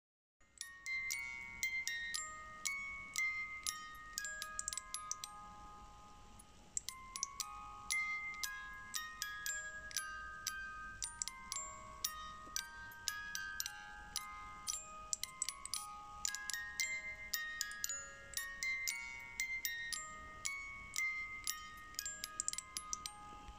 Melodia z pozytywki.